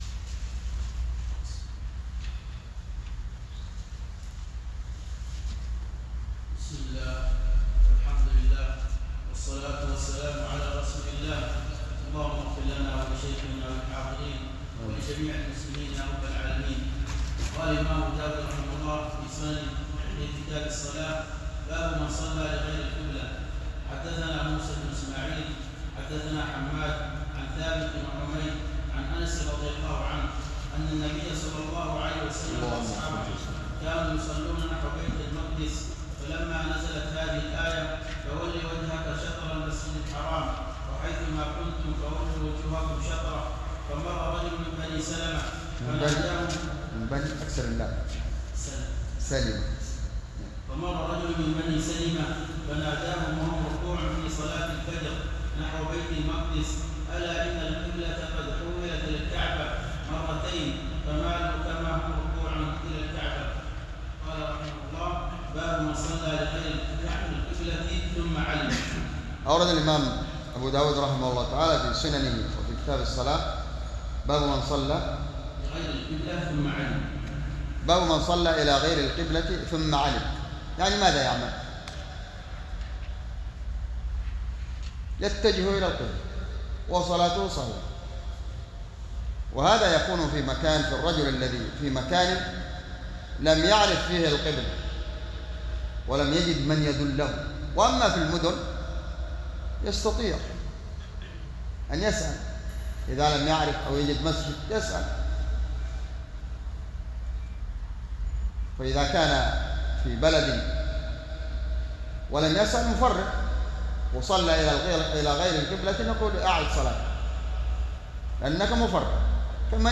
بجامع الدرسي صبيا